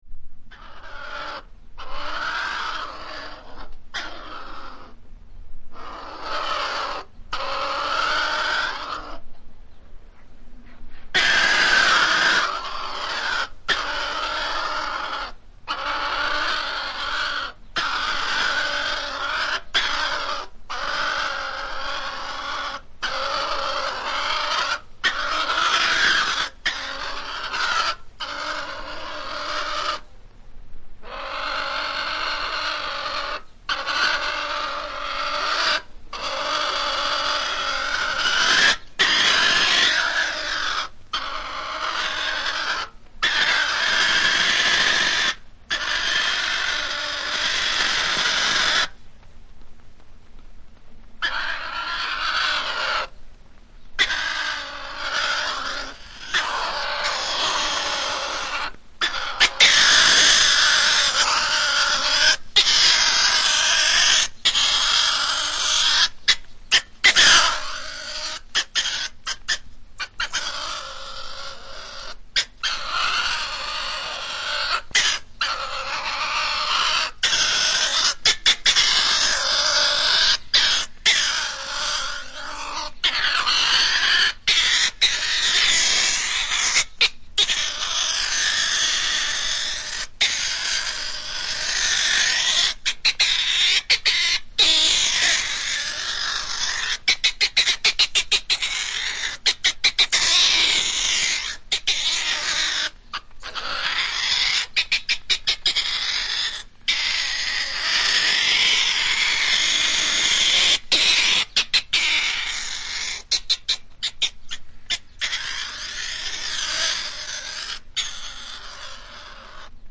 Звуки куницы
Лесная куница ворчит в зоопарке Лондона